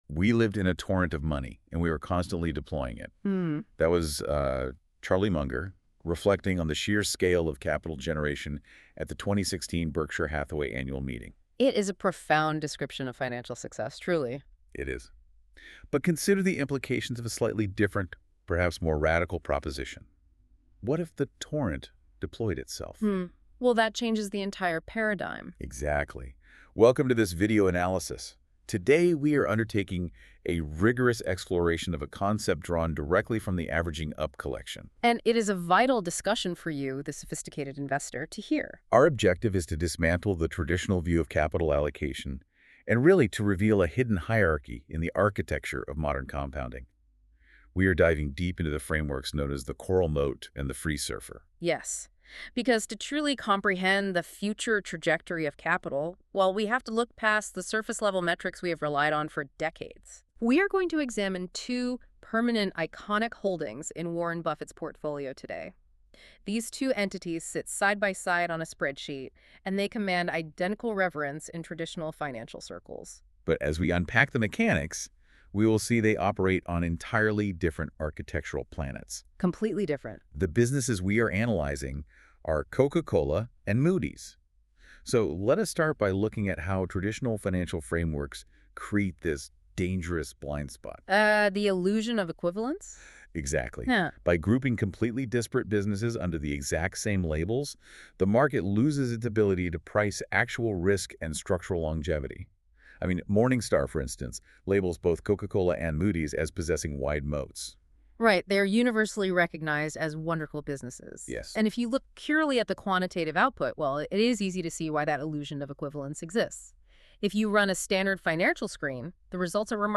Listen to the deep-dive discussion – Why Moody’s beats the Coca-Cola moat (19:37 min)